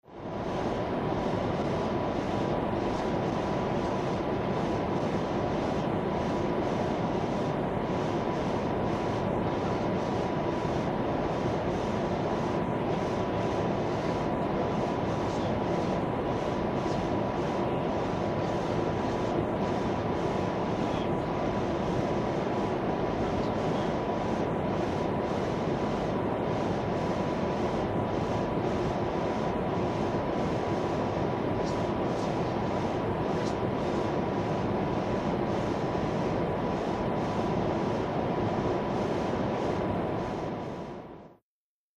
Boeing 707, interior, galley in flight